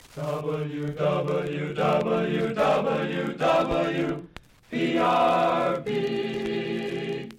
recently submitted an acetate disc of WPRB station breaks, as performed by University a cappella group
I wrote the singing station breaks.